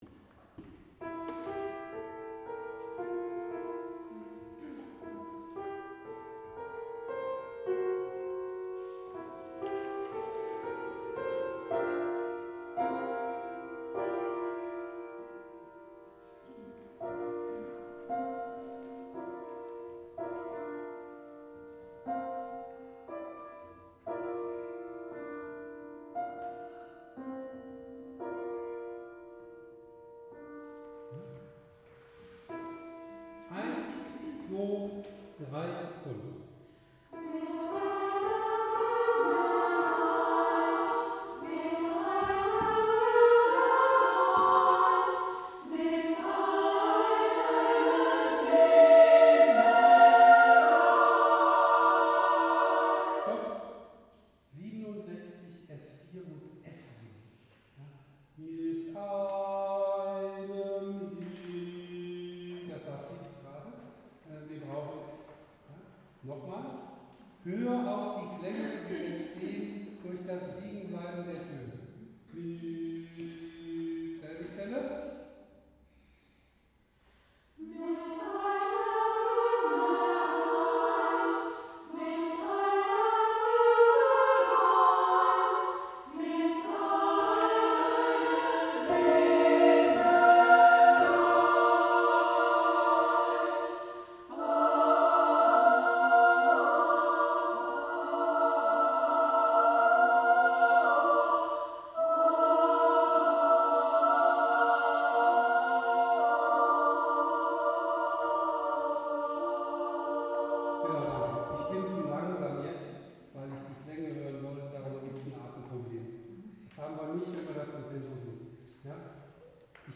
Gott-in-uns-T-63-76-Probe.mp3